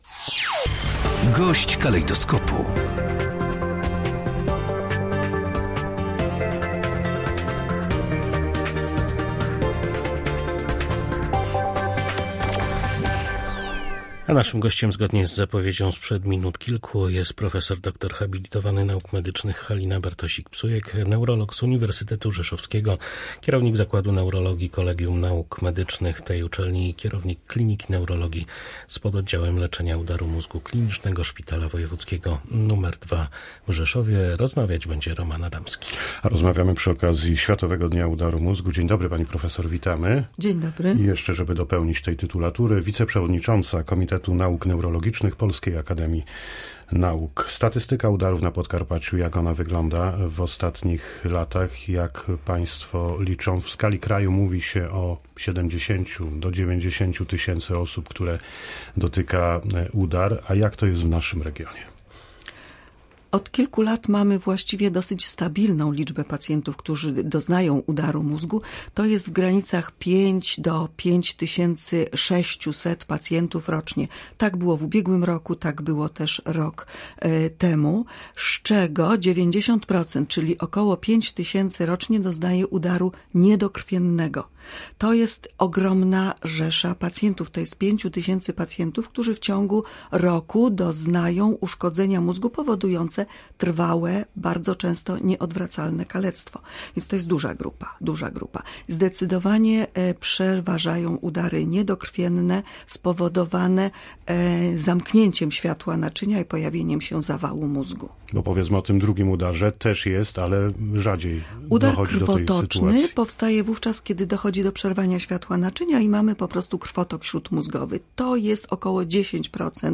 GOŚĆ DNIA.